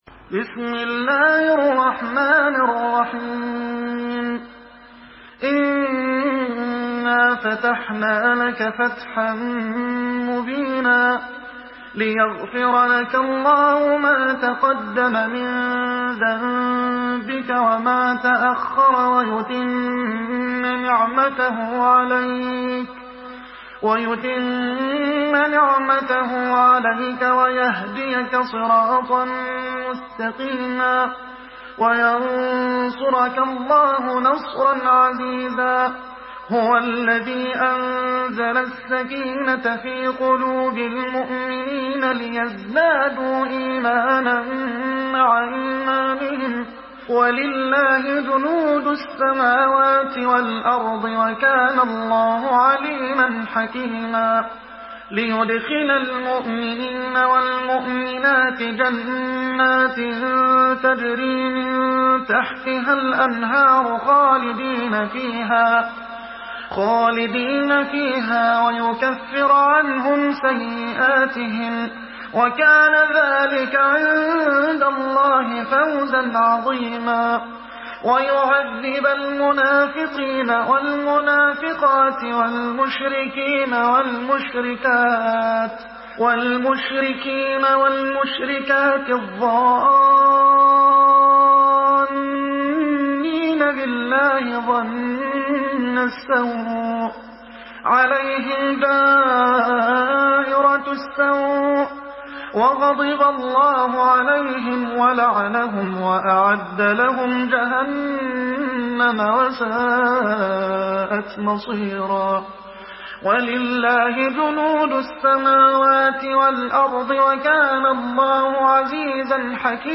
سورة الفتح MP3 بصوت محمد حسان برواية حفص
مرتل